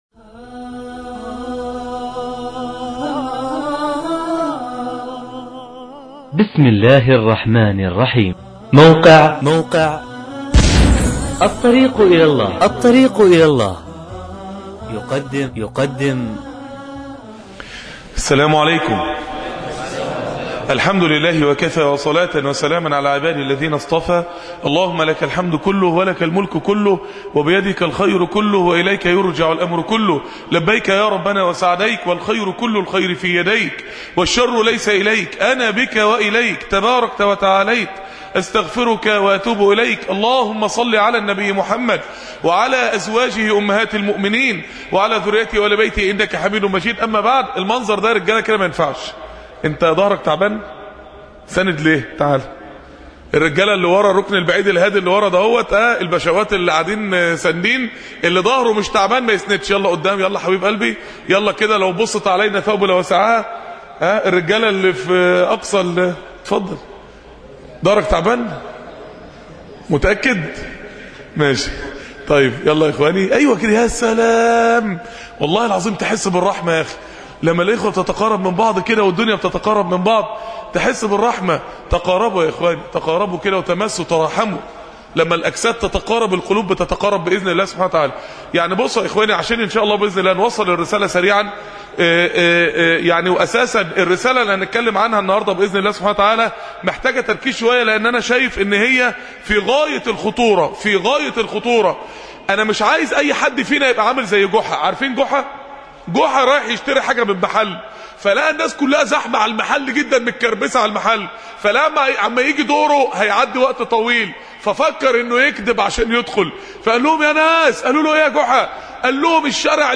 (اليوم التربوي الثاني للرجال بمسجد الصديق بالمنصورة )